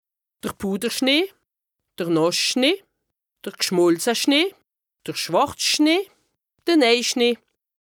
Haut Rhin
Ville Prononciation 68
Bruebach